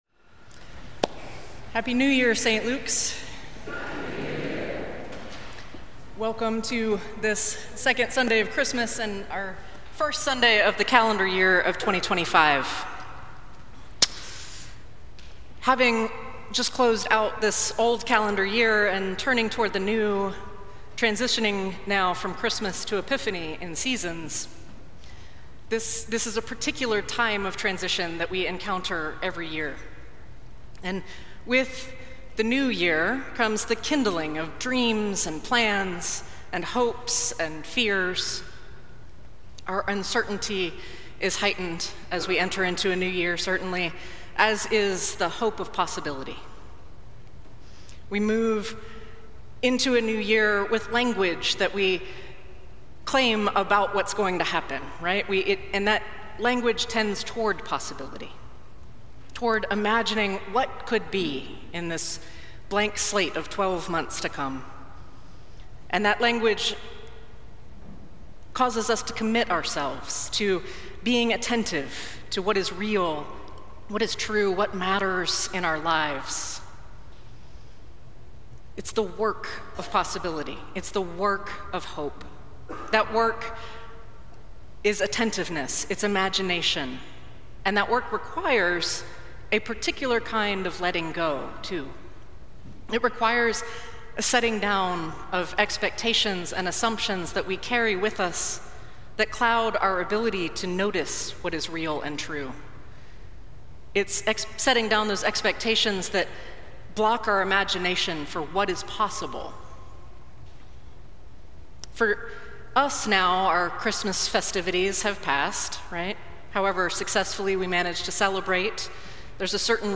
Sermons from St. Luke's Episcopal Church in Atlanta